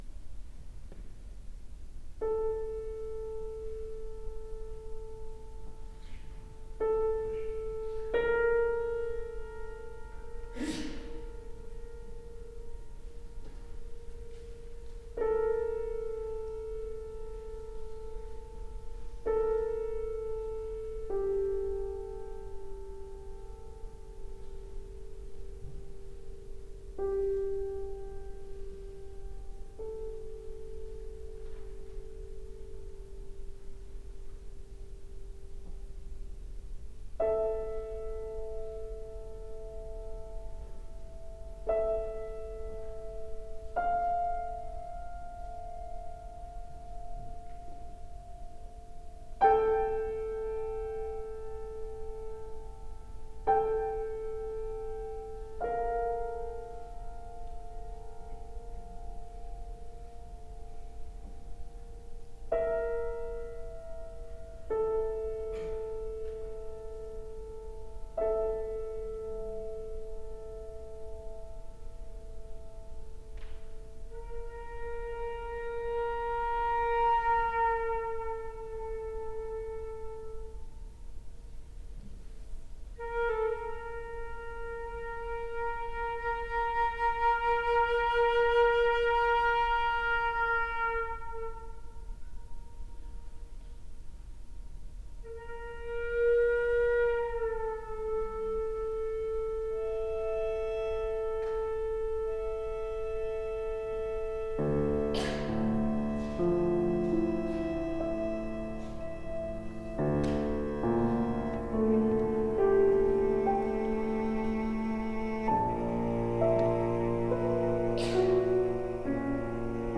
flute, cello, piano